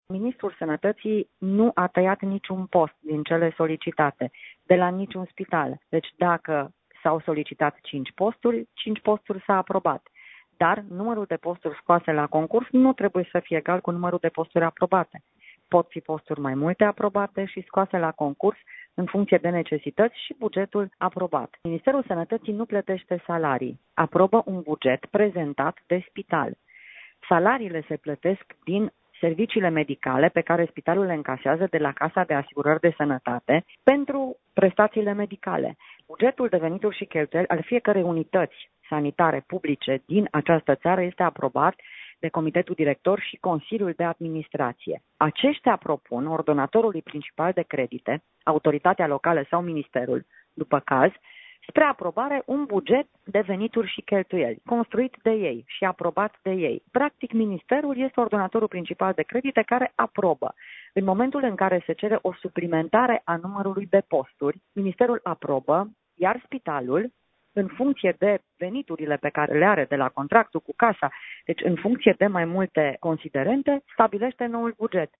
Ministrul sănătății, Sorina Pintea, a declarat, pentru postul nostru de Radio, că numărul de posturi aprobat de minister este cel cerut de spital, iar salariile se plătesc din contravaloarea serviciilor medicale prestate și încasată de la Casa de Asigurări de Sănătate.